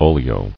[o·li·o]